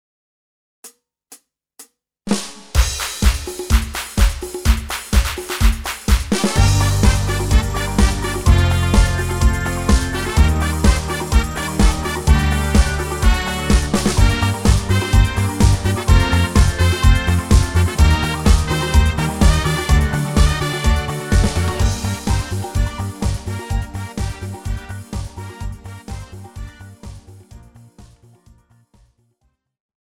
KARAOKE/FORMÁT:
Žánr: Pop
BPM: 126
Key: Gm